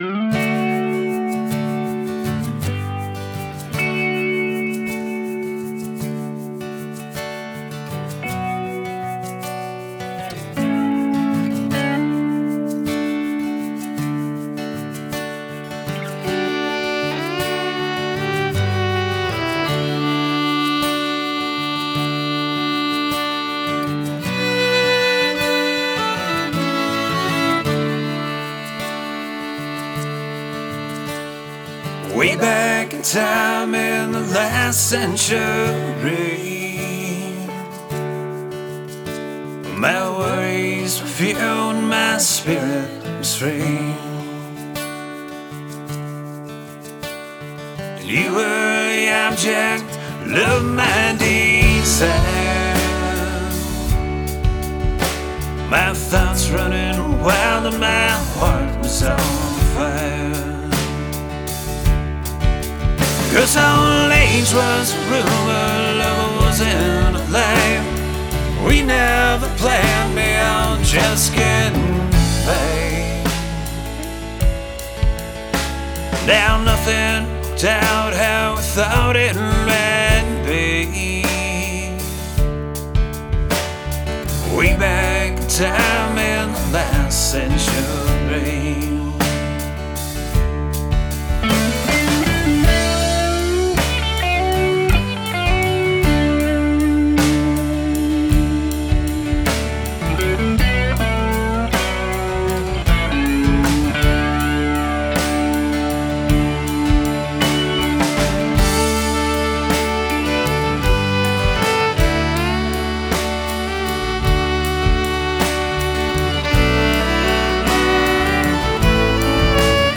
bass guitar
lead guitar
fiddle, mandolin, acoustic guitar,vocals
drums, vocals